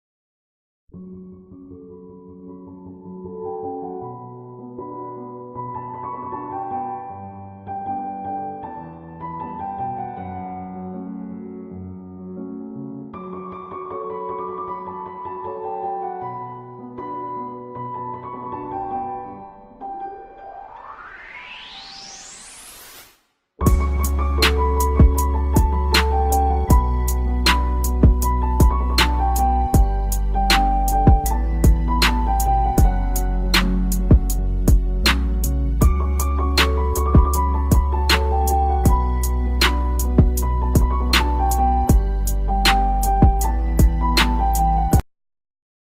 آهنگ لالا لا لا لا لا با صدای زن (غمگین)